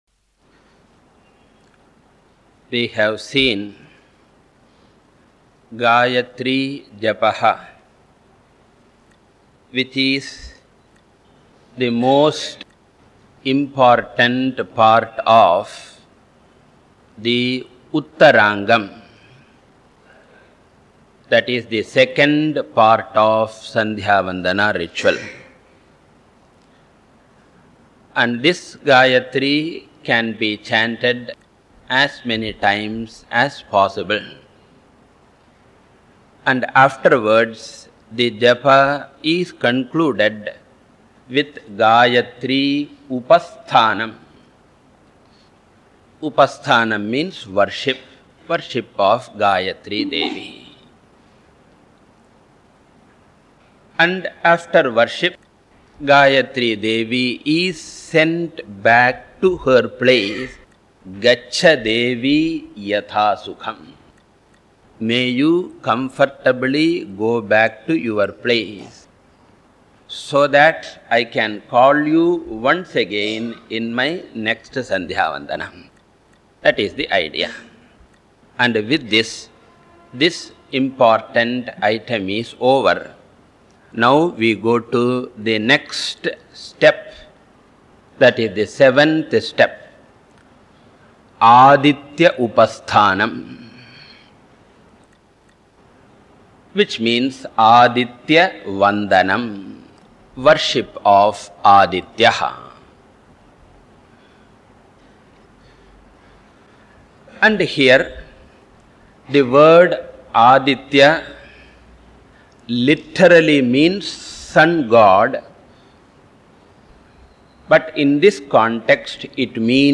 Lectures / Instructions Audio Link to download 01 Sandhyavandanam